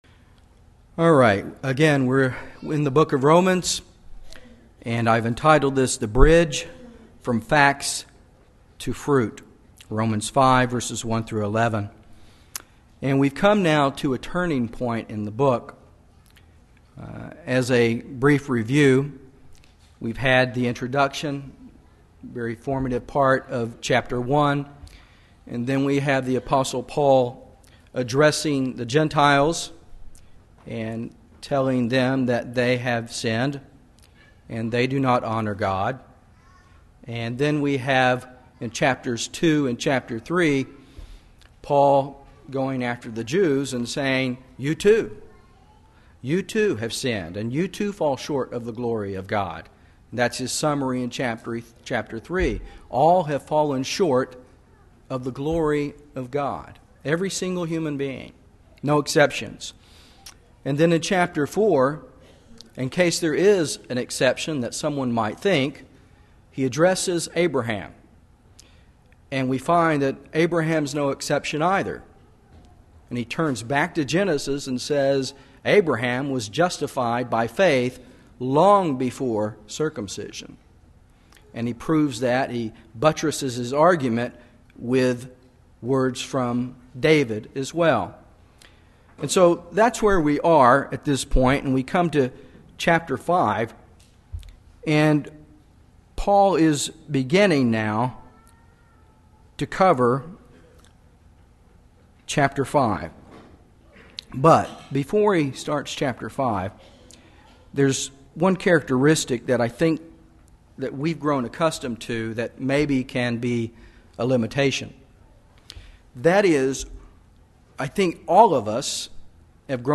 In this sermon, we examine how Romans 5:1-11 connects with the rest of the book. Paul moves from the facts of justification to the fruit of justification for we Christians through the centrality of Jesus Christ, producing a chain of rejoicing.
Given in Dallas, TX